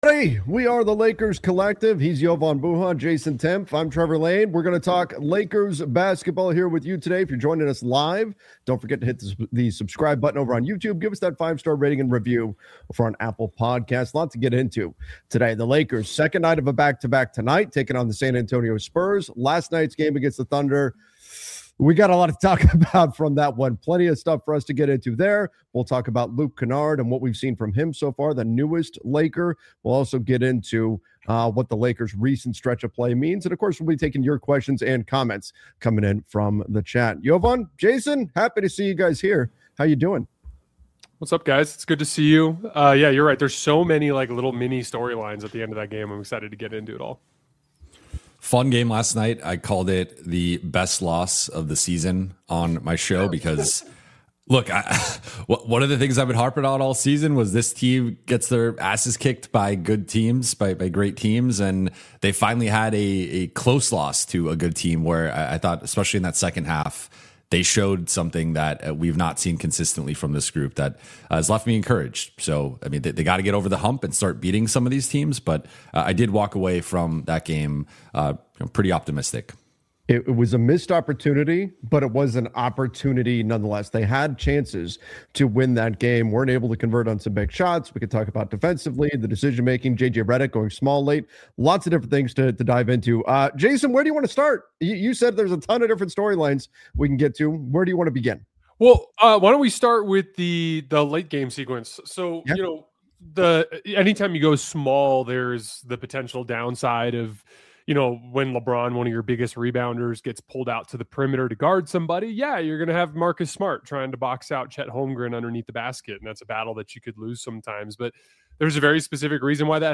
a weekly Los Angeles Lakers roundtable